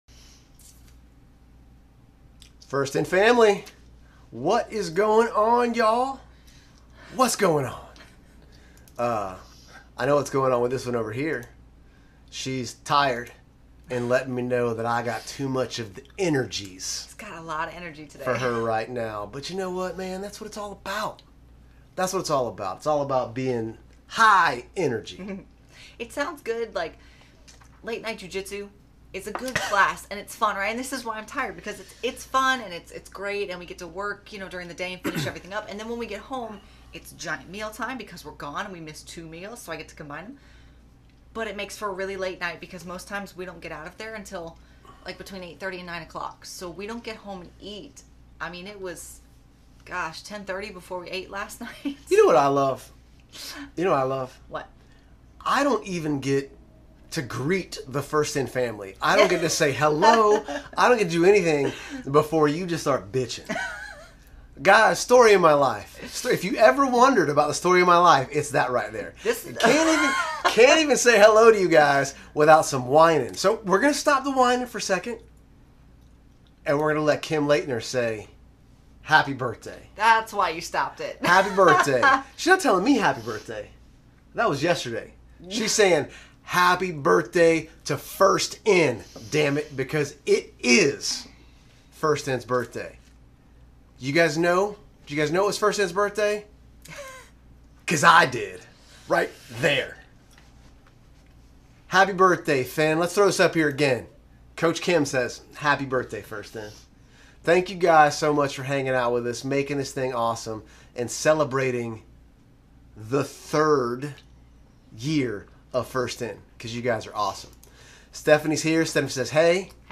It was an awesome BDAY edition of the Q+A! We talked a little bit about getting Zucked, why you need to subscribe to FIN LIVE, how First In came to be, and just how damn amazing this Fam is! *WARNING* we shoot it straight and both ourselves and quite a few members of the fam expressed some STRONG opinions on this one lol!